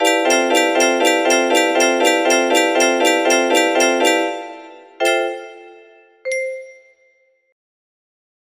crowds of people music box melody